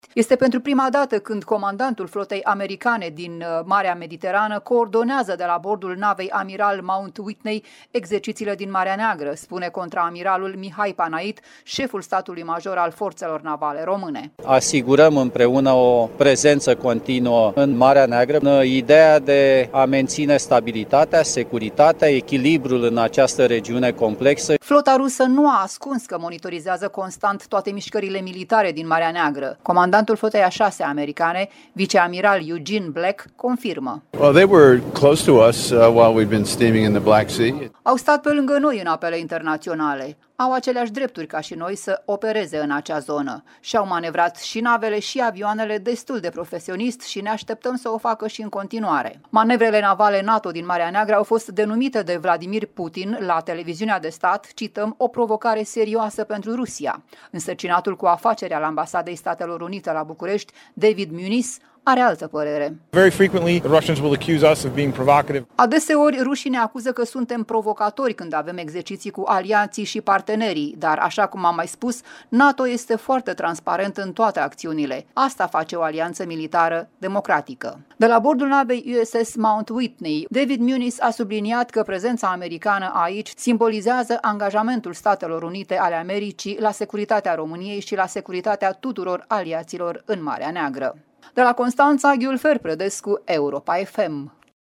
Este pentru prima dată când comandantul Flotei americane din Marea Mediterană a coordonat de la bordul navei amiral Mount Whitney exercițiile din Marea Neagră, spune contraamiral Mihai Panait, șeful Statului major al Forțelor Navale Române:
Comandatului Flotei a VI a americană, viceamiral Eugene Black, confirmă: